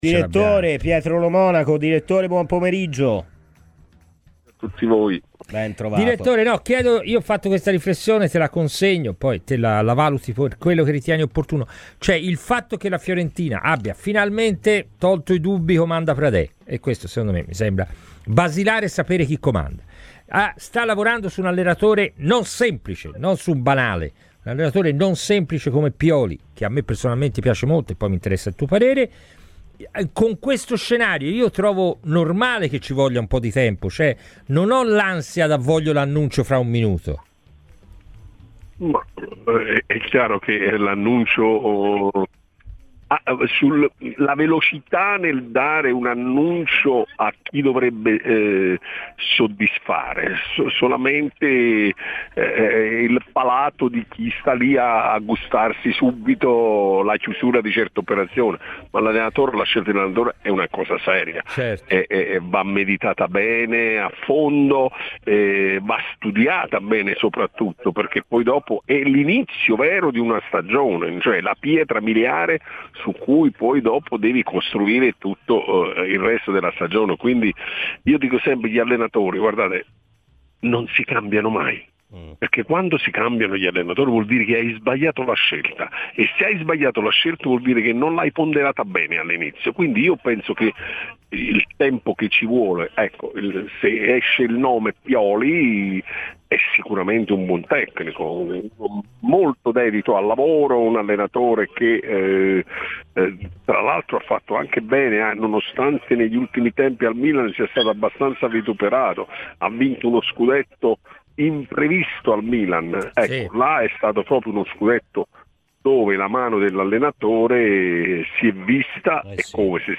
Il dirigente sportivo